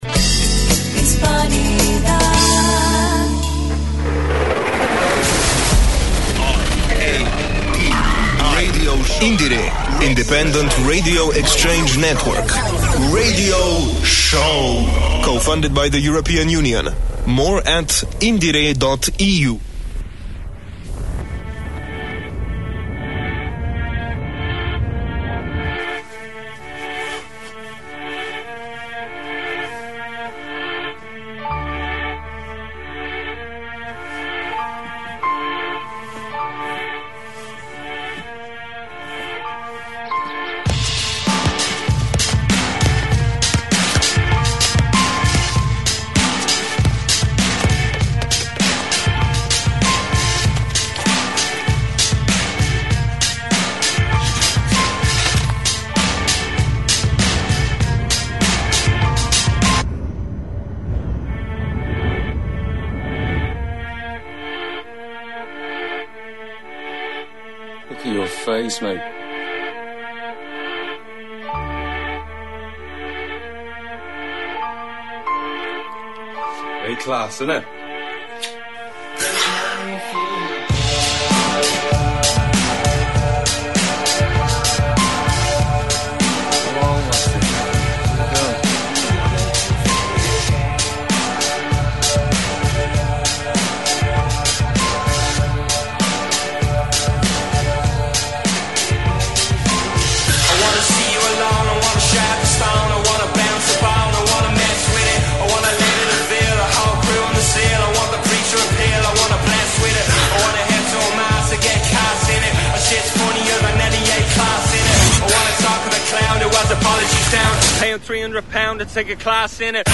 TITLE & NUMBER OF THE BROADCAST: INDIE-RE #115 by Near FM Dublin SHORT SUMMARY:The 115th Irish edition of IndieRe promises to be a special episode, featuring a lineup of new releases and interviews with emerging talents from the Irish music scene.